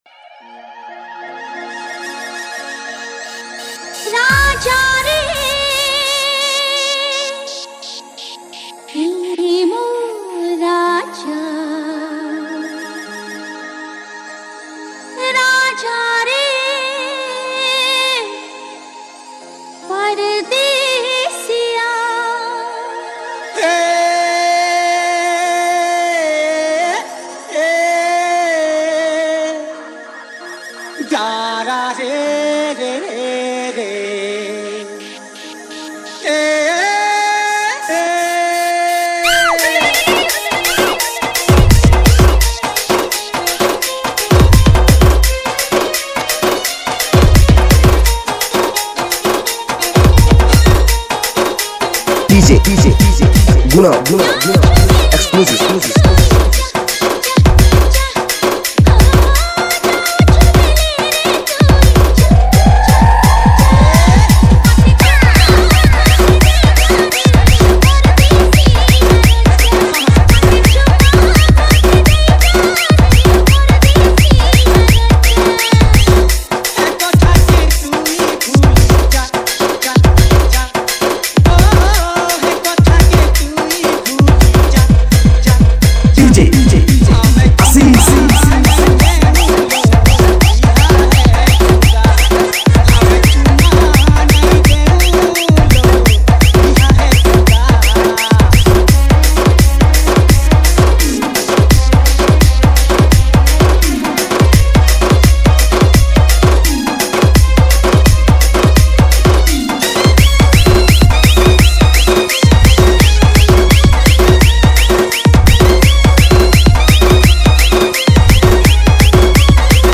SAMBALPURI LOVE DJ REMIX